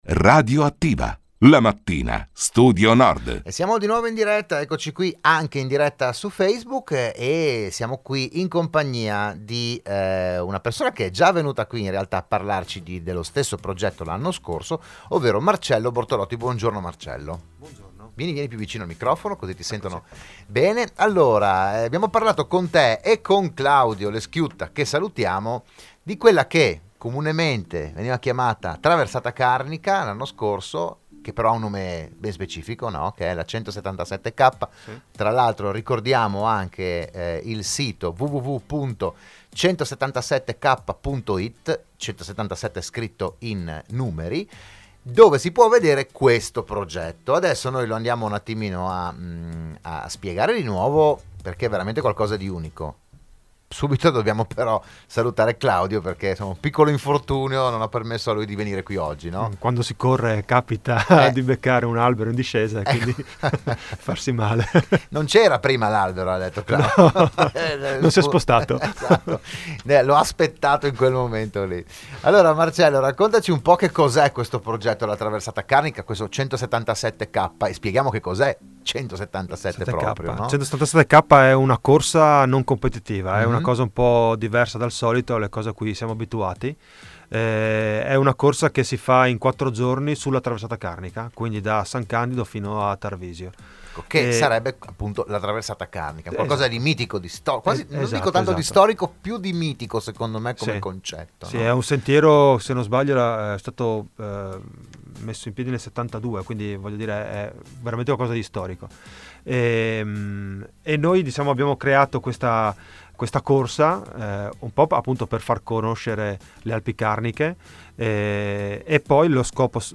Appuntamento da mercoledì 31 luglio a domenica 4 agosto. Il PODCAST e il VIDEO dell'intervento a Radio Studio Nord